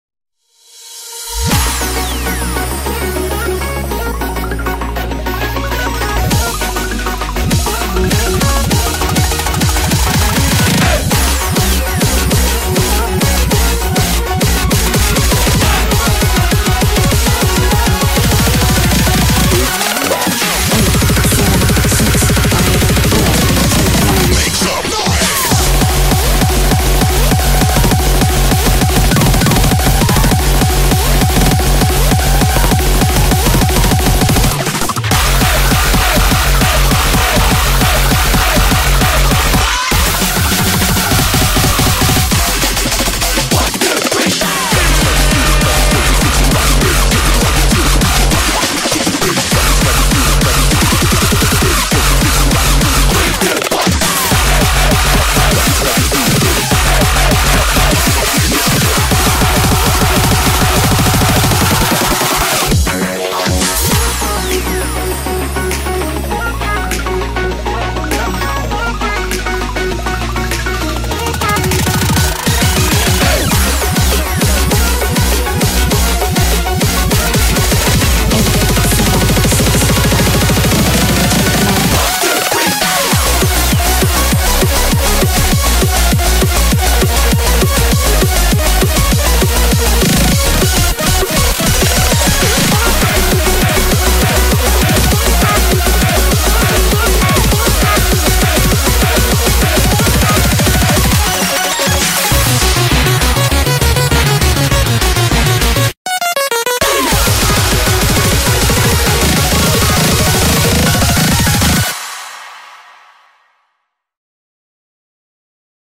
BPM100-200
Audio QualityPerfect (Low Quality)